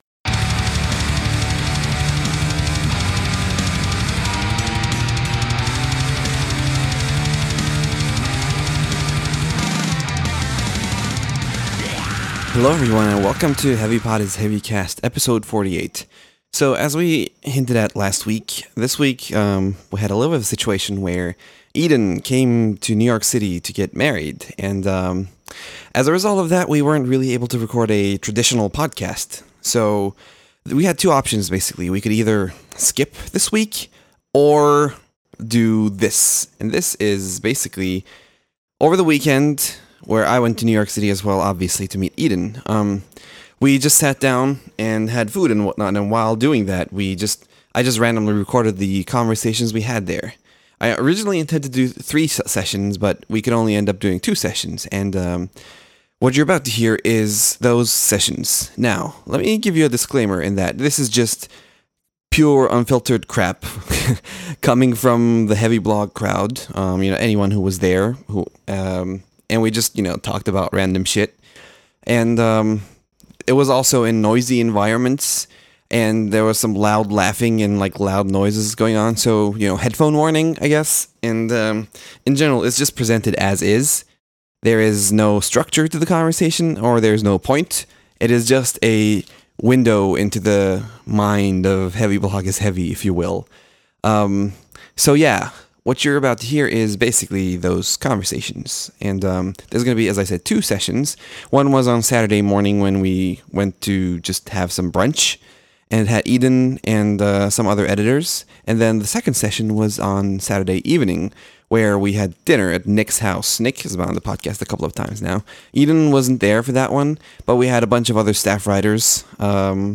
So, this week you get two random recordings of blog people having pointless conversations in noisy environments.